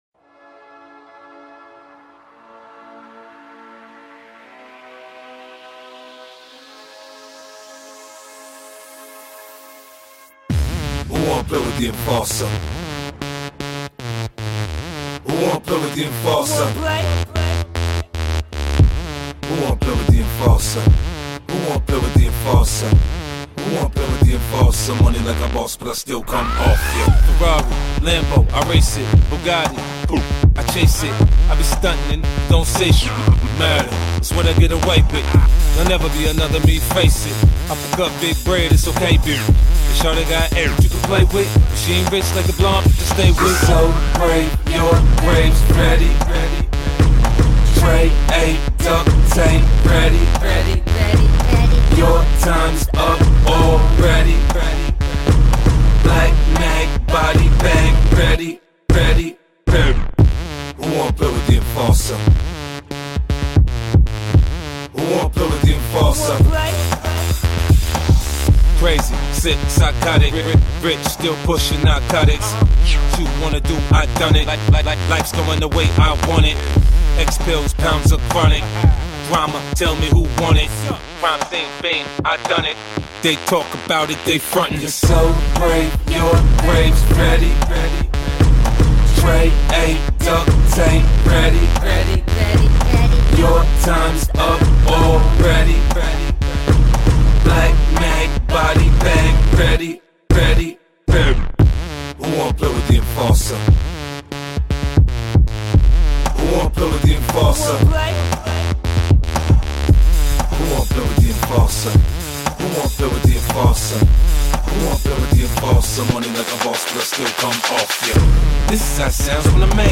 rap музыка